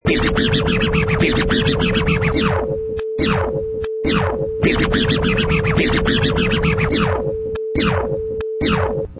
Sound clips: Vinyl Crazy Scratch 105 bpm 1
Professional killer vinyl scratch perfect for sampling, mixing, music production, timed to 105 beats per minute
Product Info: 48k 24bit Stereo
Category: Musical Instruments / Turntables
Try preview above (pink tone added for copyright).
Vinyl_Crazy_Scratch_105_bpm_1.mp3